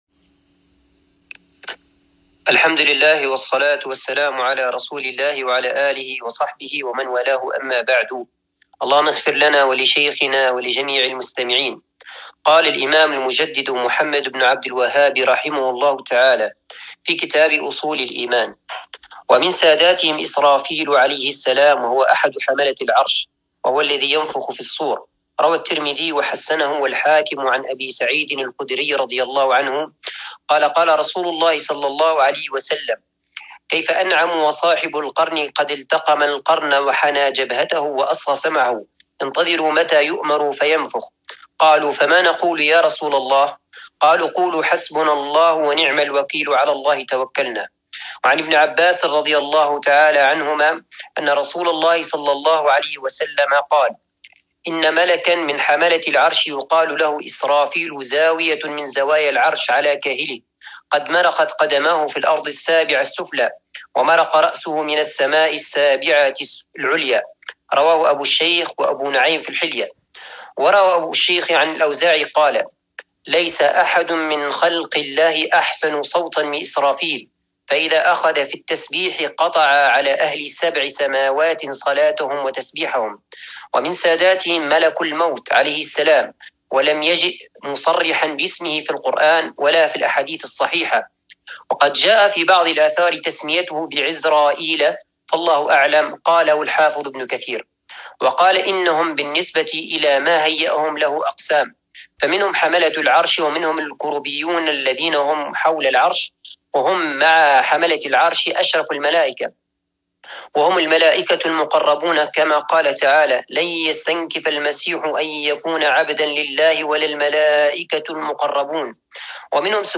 الدرس السابع في شرح كتاب أصول الإيمان